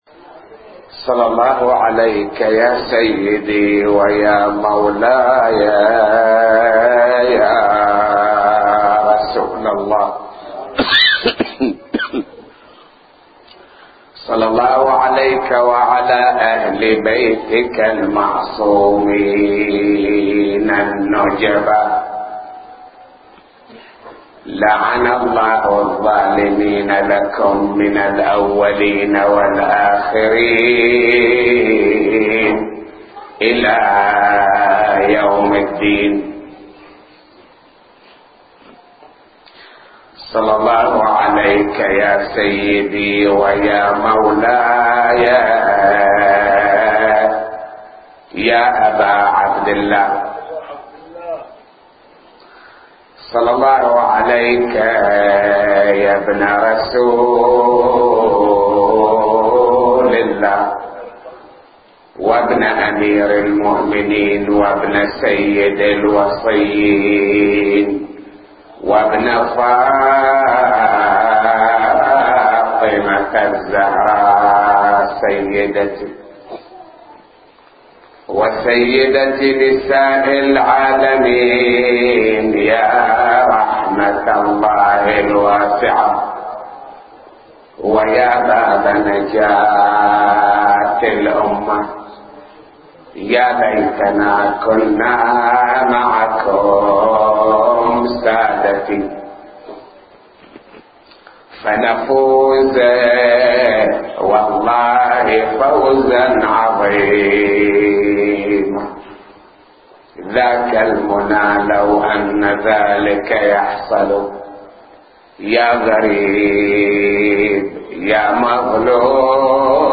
نواعي وأبيات حسينية – 5